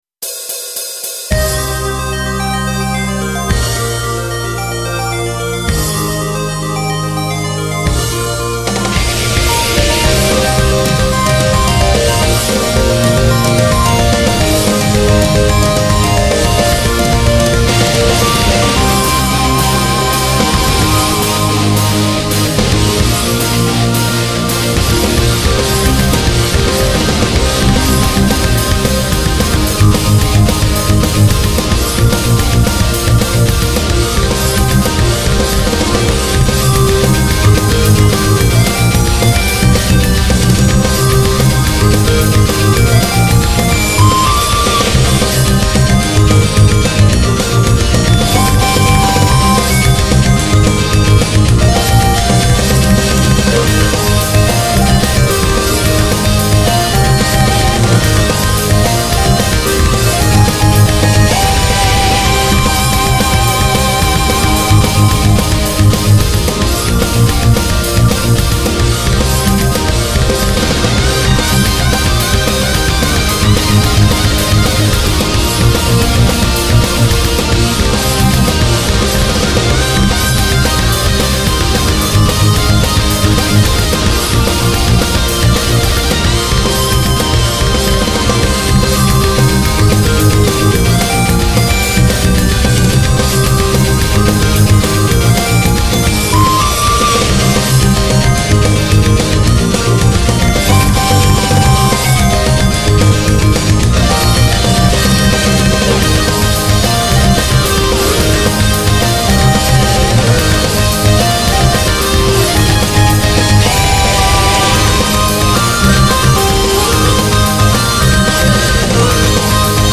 強烈なアレンジ。
音源モジュール YAMAHA MU2000